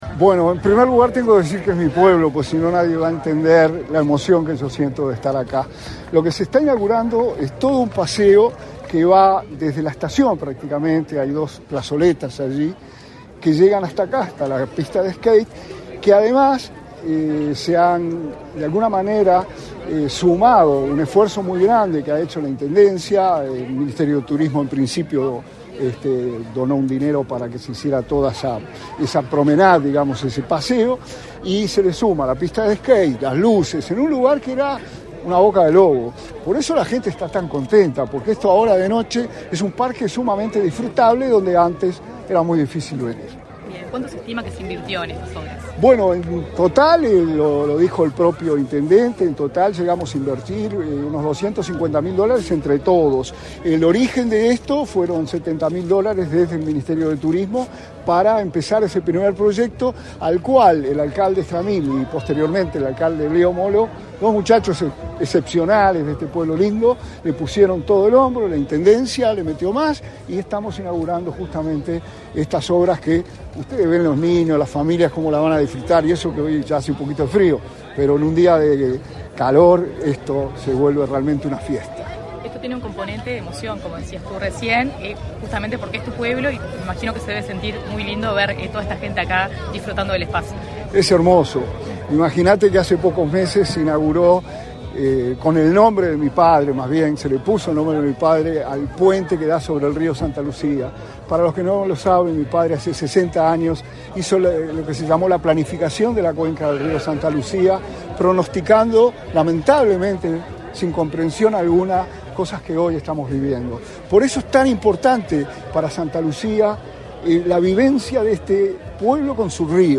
Declaraciones a la prensa del subsecretario de Turismo, Remo Monzeglio
Tras el evento, el subsecretario de Turismo, Remo Monzeglio, realizó declaraciones a la prensa.